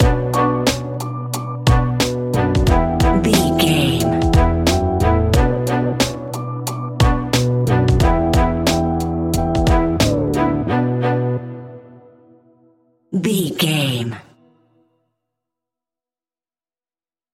Aeolian/Minor
chilled
laid back
groove
hip hop drums
hip hop synths
piano
hip hop pads